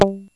_GUITAR PICK 1.wav